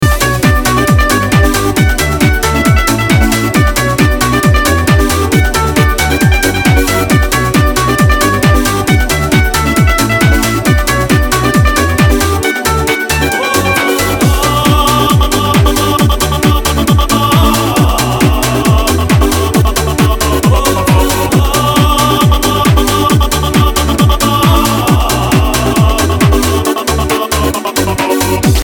• Качество: 320, Stereo
без слов
инструментальные
русская попса
Фрагмент инструментальной композиции